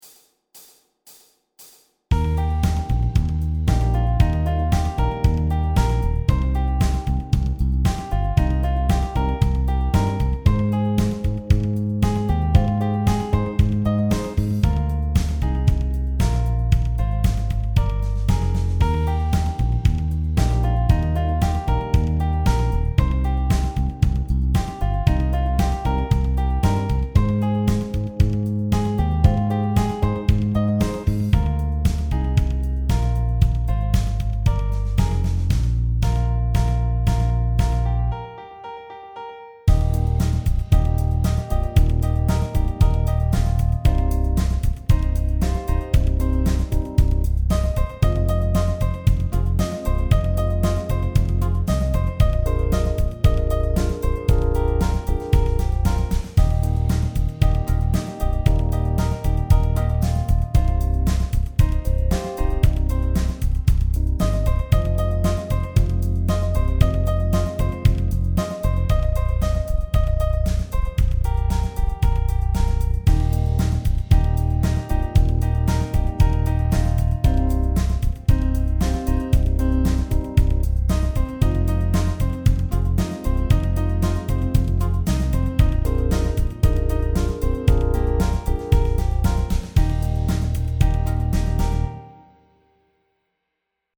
Trompete--> Playback